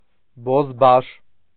Bozbash (Armenian: բոզբաշ [bozˈbɑʃ]